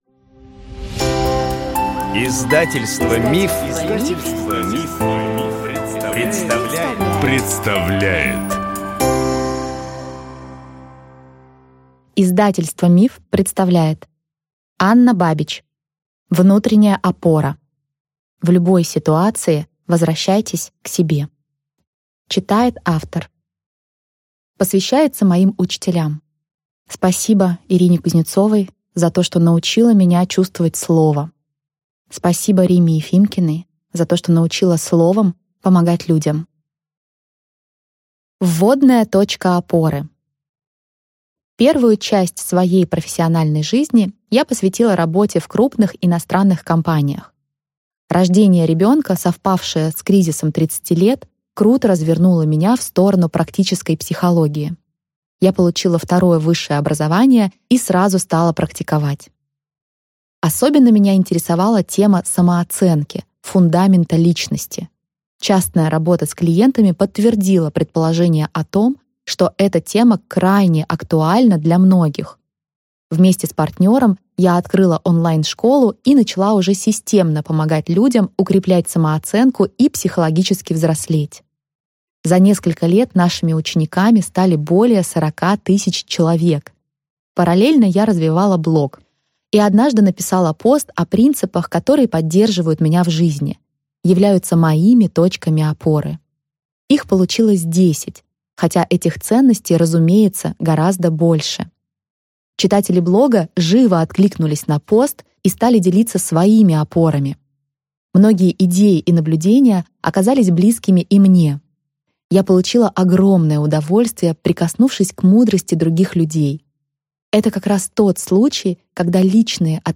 Аудиокнига Внутренняя опора. В любой ситуации возвращайтесь к себе | Библиотека аудиокниг